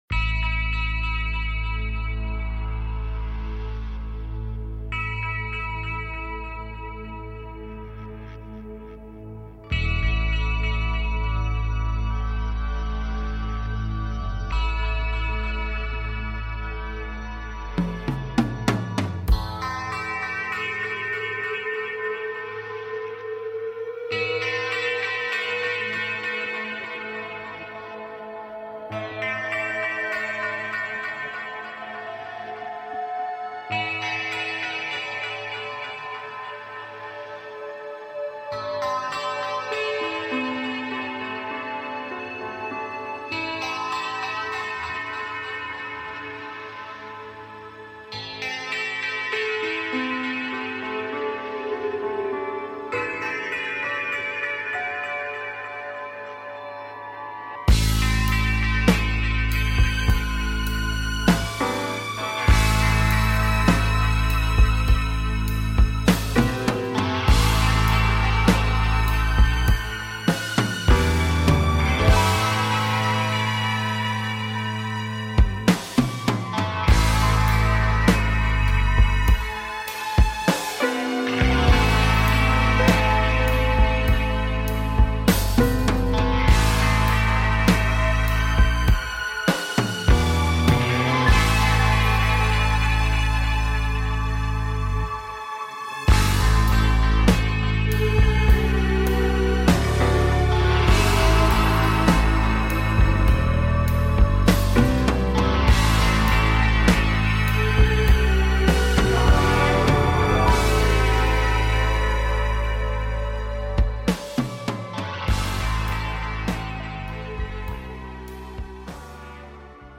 Talk Show Episode
A show based on Timelines and manifesting Timelines while taking caller questions.